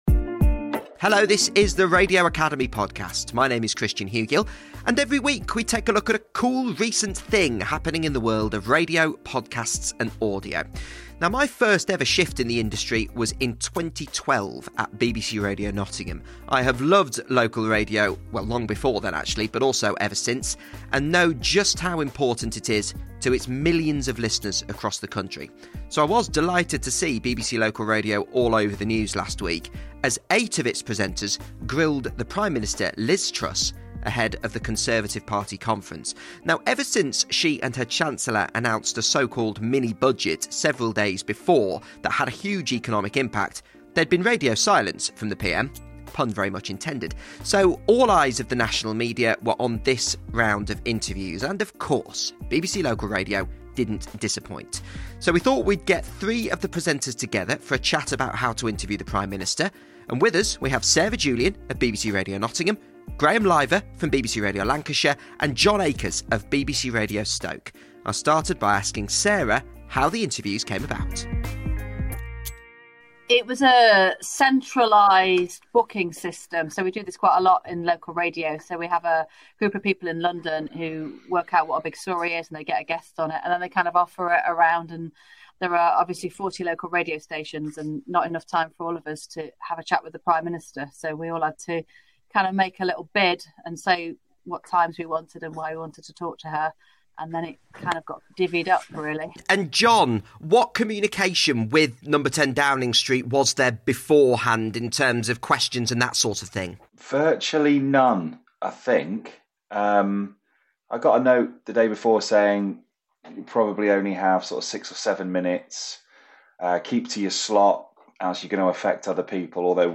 speaks to three of the eight presenters who held those interviews